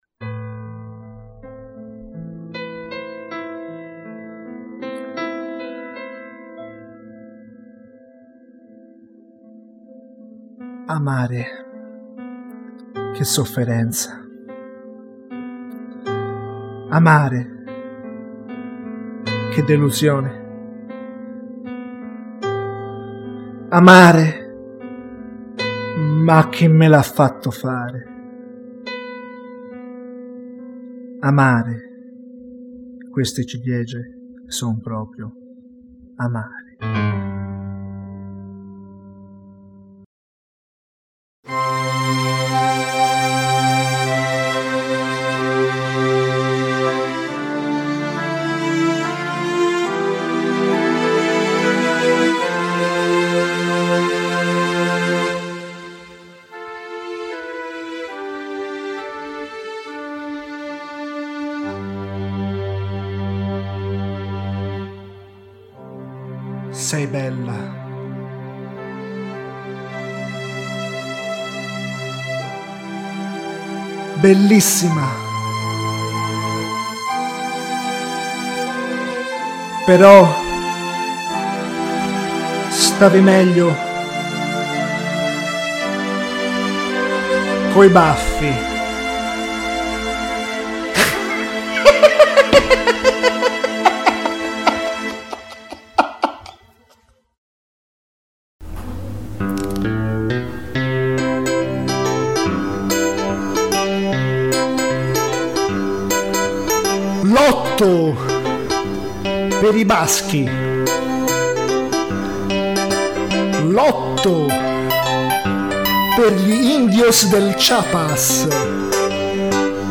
Per una volta passo dalla lirica alla prosa (ehm) e mi dedico alla recitazione di alcune poesie che ho scritto in un albergo di Bilbao.
alla tastiera